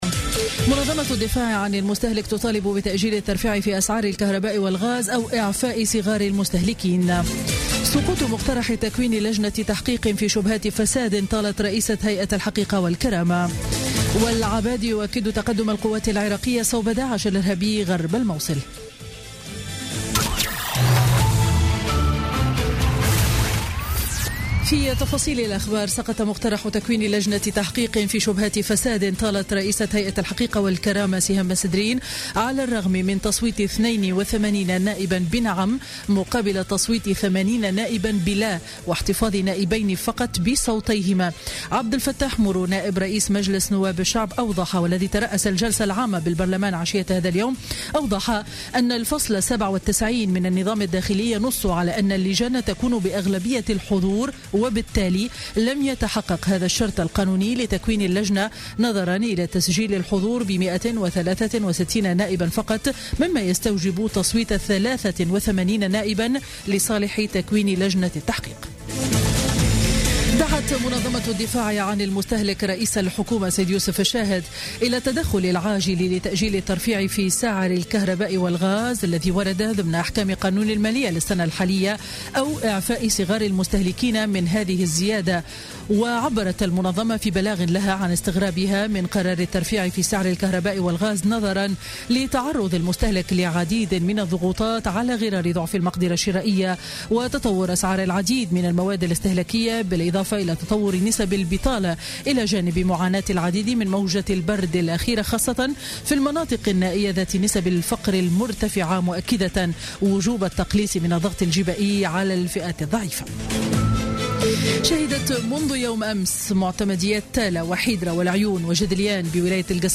نشرة أخبار السابعة مساء ليوم الثلاثاء 17 جانفي 2017